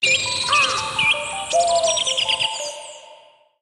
Index of /phonetones/unzipped/Sony/Xperia-T3-D5103/alarms